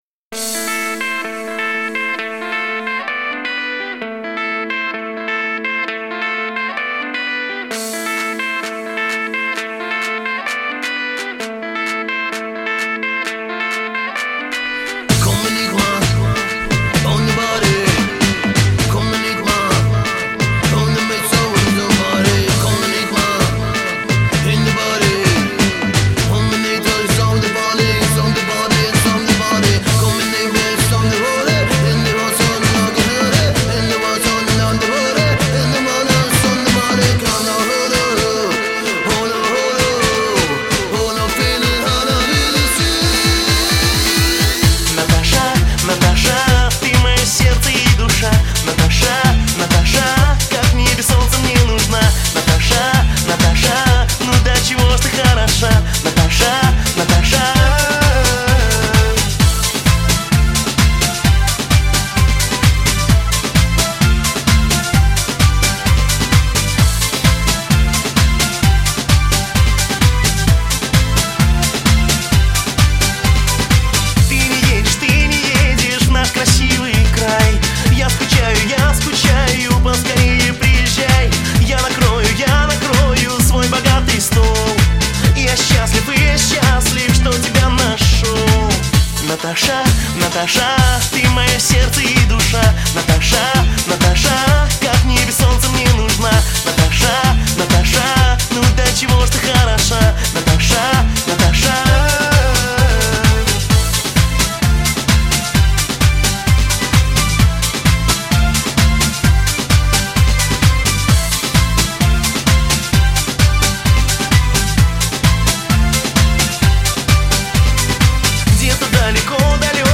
• Жанр песни: Жанры / Поп-музыка